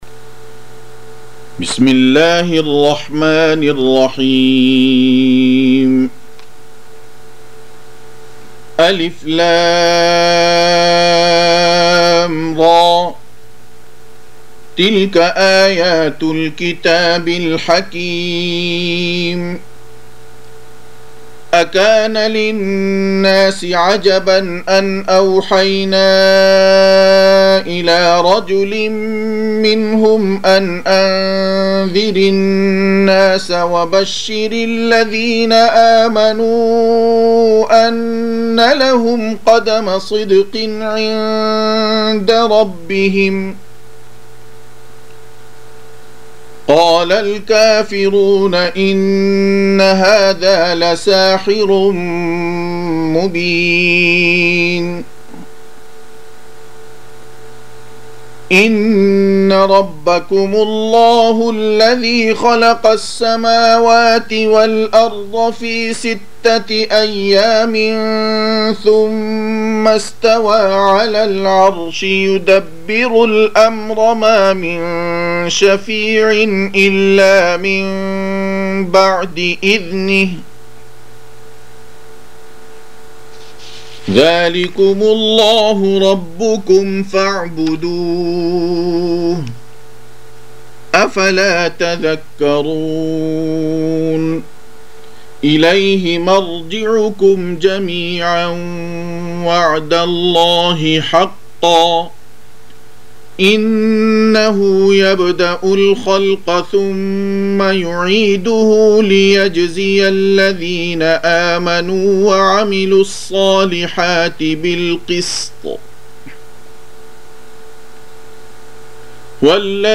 10. Surah Y�nus سورة يونس Audio Quran Tarteel Recitation
Surah Sequence تتابع السورة Download Surah حمّل السورة Reciting Murattalah Audio for 10.